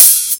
Hat Open.wav